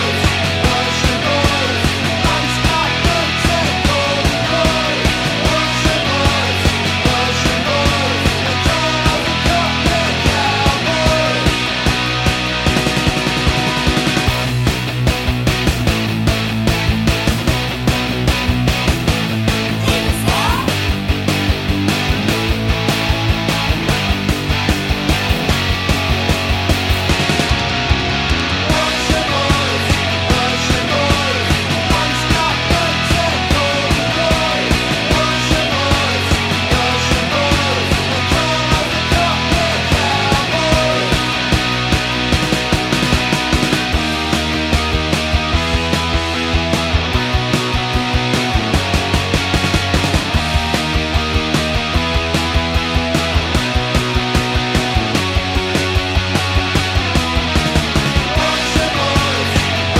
Punk